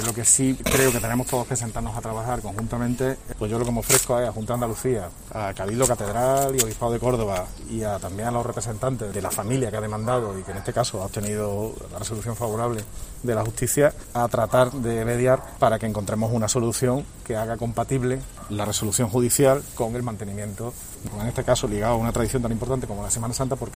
En declaraciones a los periodistas, Bellido ha recalcado este jueves su respeto a "las decisiones judiciales", más aún cuando desde el Ayuntamiento "no somos parte de ese proceso".
José María Bellido, alcalde de Córdoba